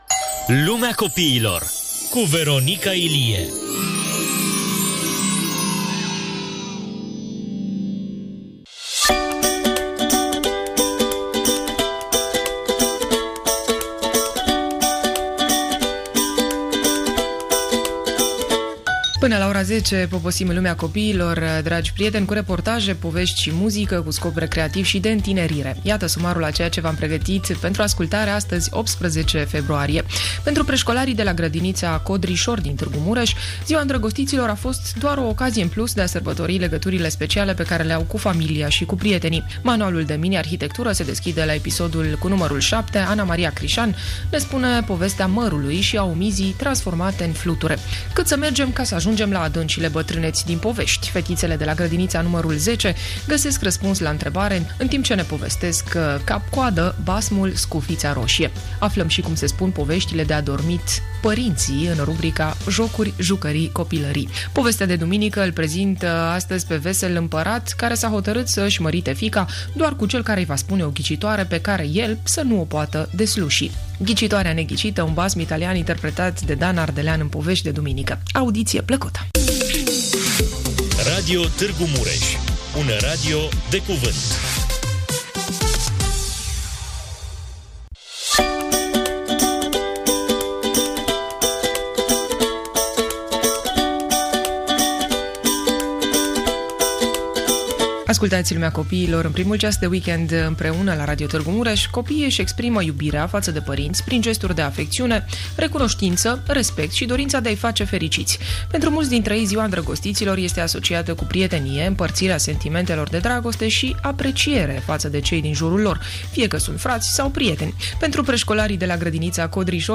Dimineața de duminică începe cu o incursiune în „Lumea copiilor”, cu reportaje, povești și muzică, dedicate tuturor copiilor și celor cu spiritul tânăr.